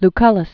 (l-kŭləs), Lucius Licinius 110?-57?